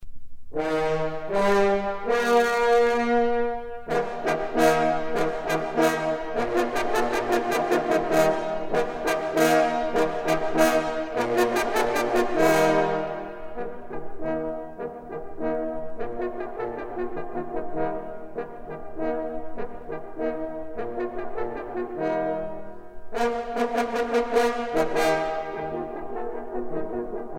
circonstance : vénerie
Pièce musicale éditée